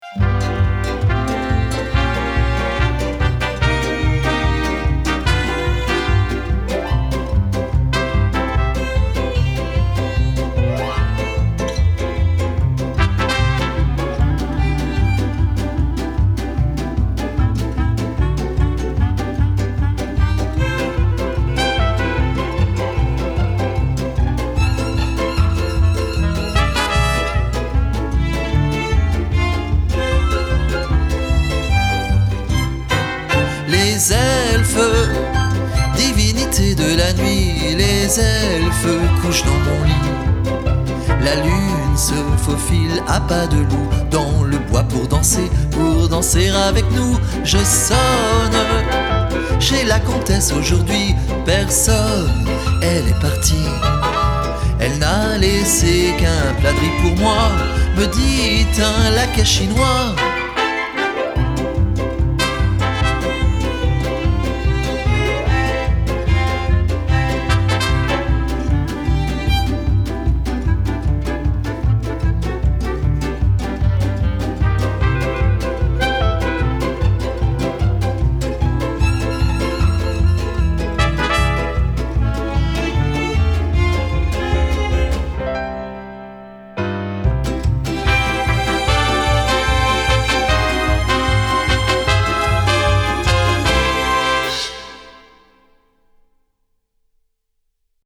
La version chantée avec les trous (expert)